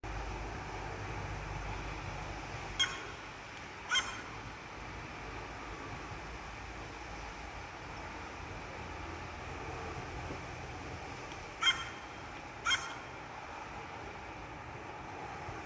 ворона, Corvus corone cornix
Administratīvā teritorijaRīga
СтатусСлышен голос, крики